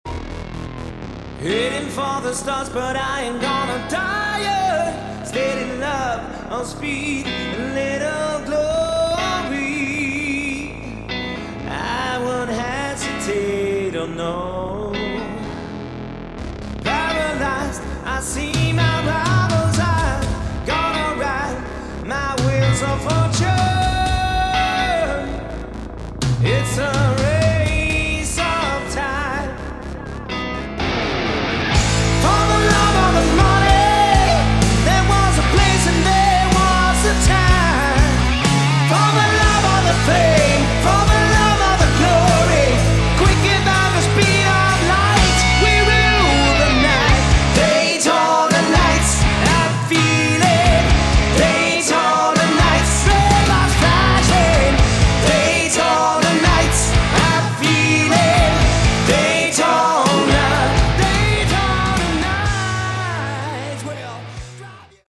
Category: Hard Rock / Melodic Metal
vocals
lead guitars
bass
drums